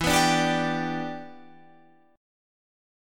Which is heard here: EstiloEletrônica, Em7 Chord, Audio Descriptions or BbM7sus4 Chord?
Em7 Chord